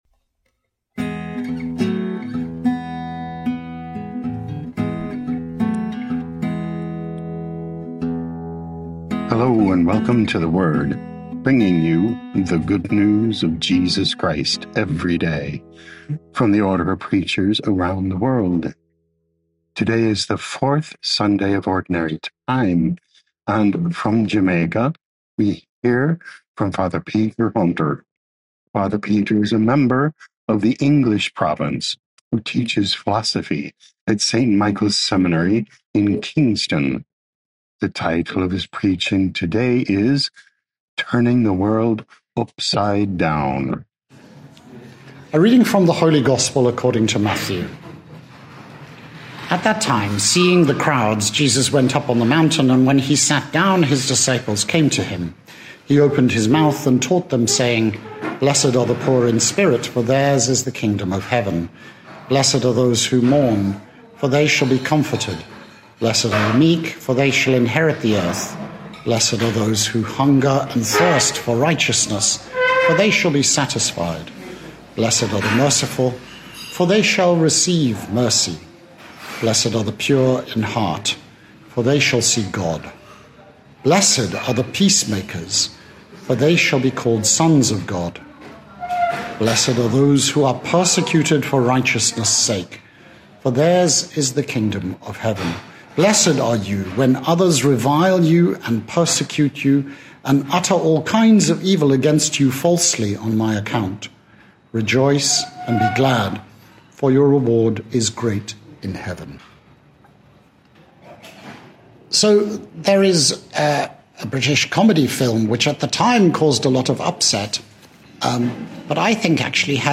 1 Feb 2026 Turning the World Upside Down Podcast: Play in new window | Download For 1 February 2026, The Fourth Sunday in Ordinary Time, based on Matthew 5:1–12a, sent in from Kingston, Jamaica.